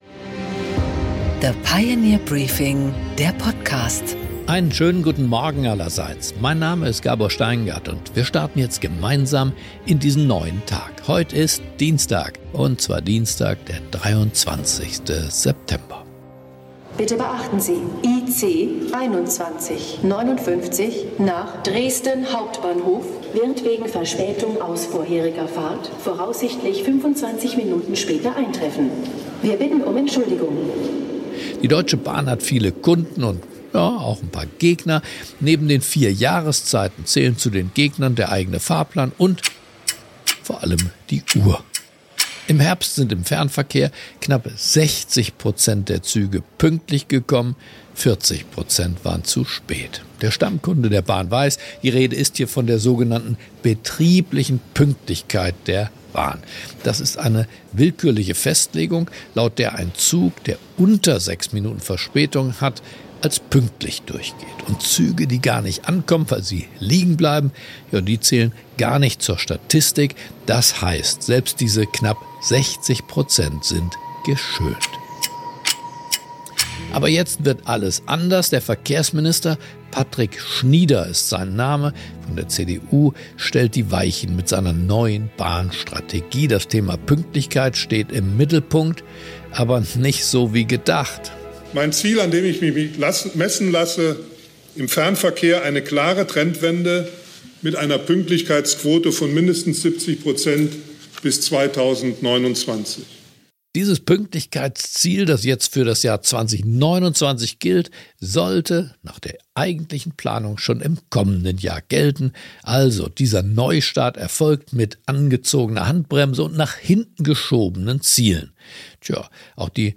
Gabor Steingart präsentiert das Pioneer Briefing
Im Gespräch: